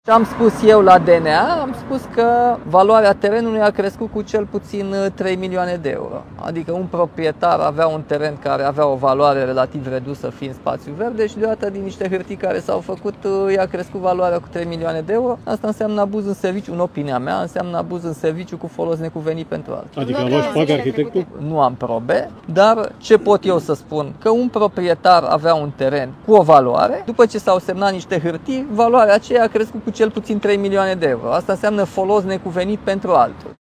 Primarul general al Capitalei, Nicușor Dan, explică de ce a sesizat DNA ul în cazul unei construcții din cartierul bucureștean Primăverii, ridicată pe spațiul verde deși legea interzice așa ceva.